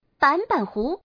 Index of /client/common_mahjong_tianjin/mahjongwuqing/update/1169/res/sfx/changsha/woman/